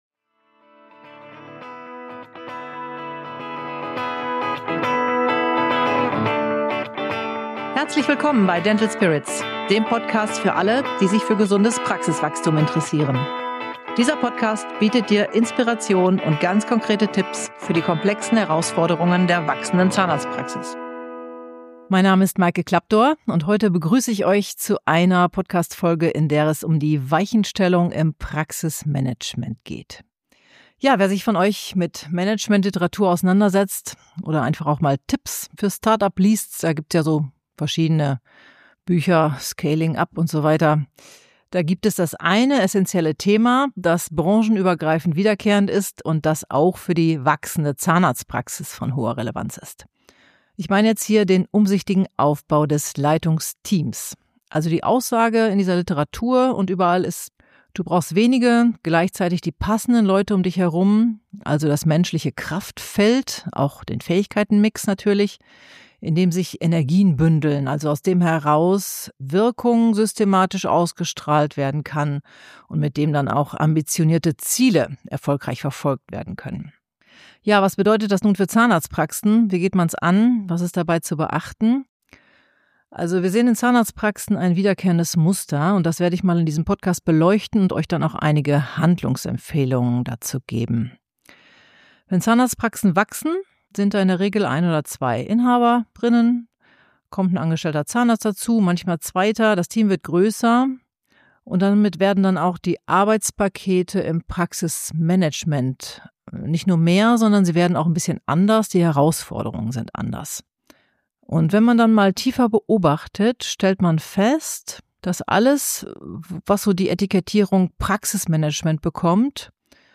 In dieser Solo-Folge